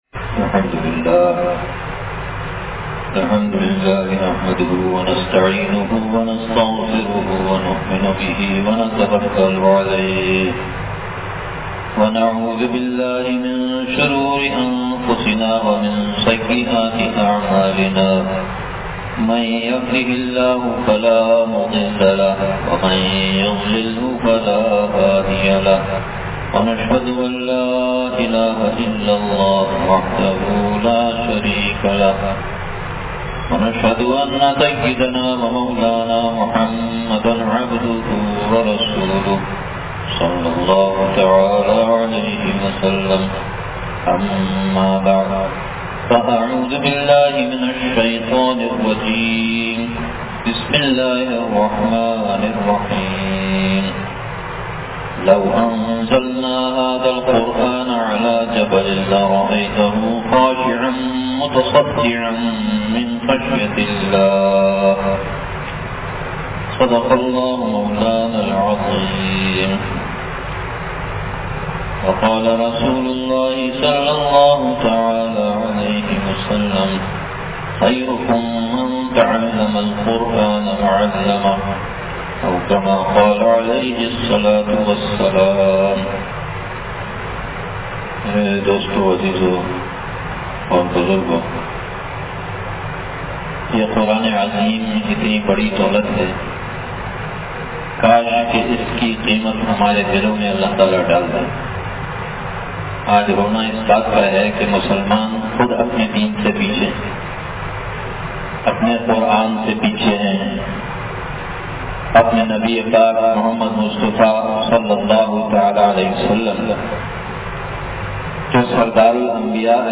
تراویح میں ختمِ قرآن کریم کے موقع پر بیان مدرسہ ابو بکر مرکز امداد و اشرف نزد معمار ہاؤسنگ کراچی